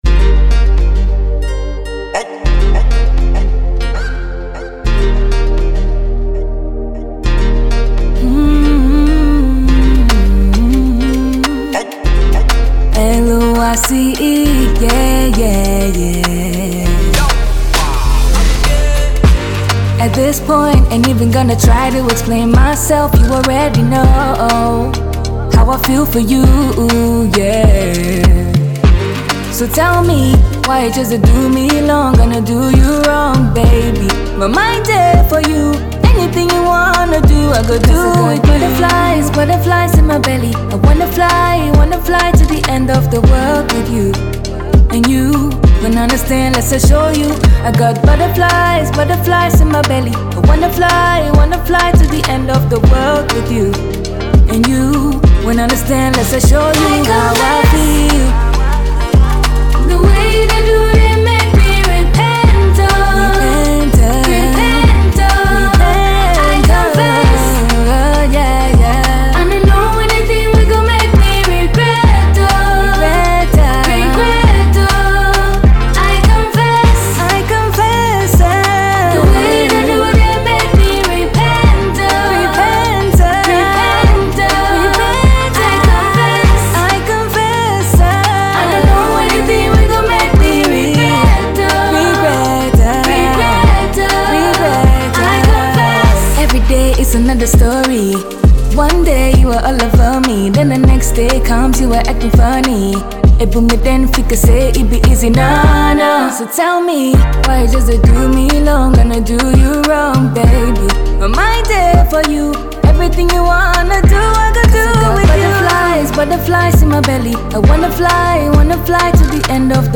a Ghanaian vocalist
a melodic Afrobeat that speaks from the soul.